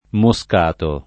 moscato [ mo S k # to ]